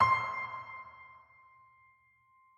piano-sounds-dev
c5.mp3